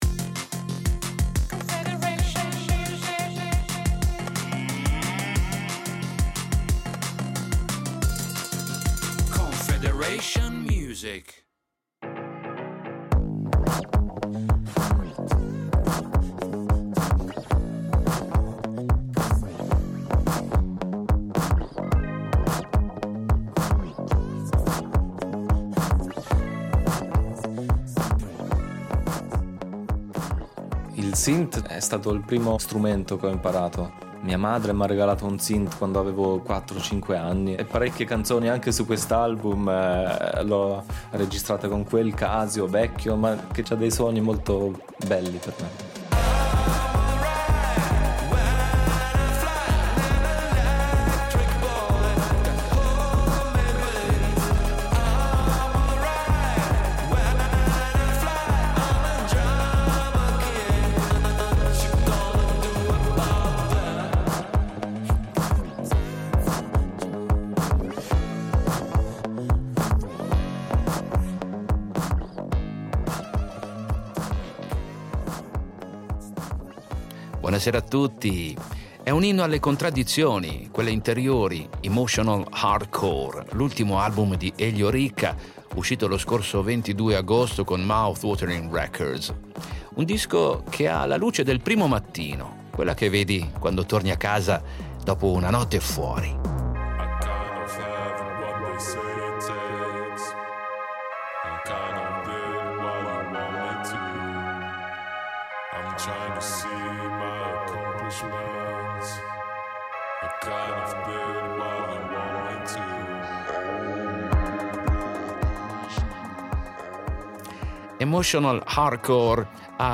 Musica pop